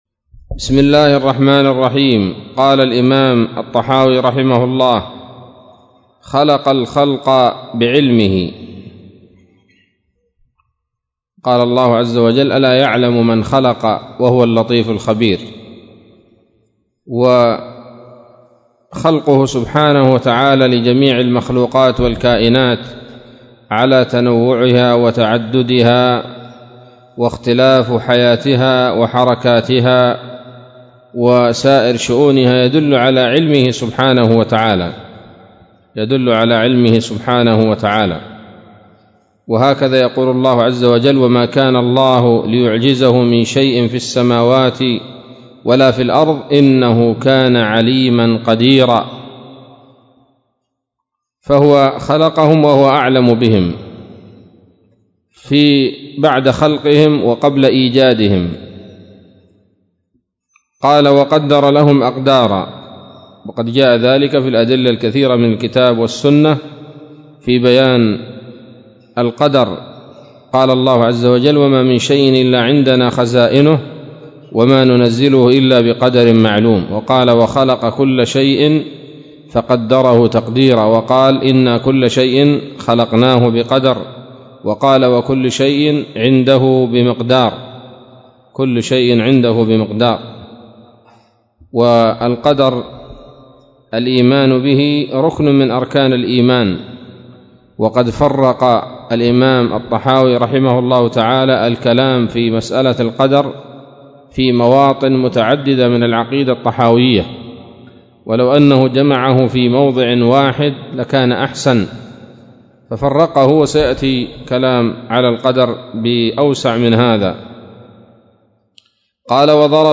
الدرس الثامن من شرح العقيدة الطحاوية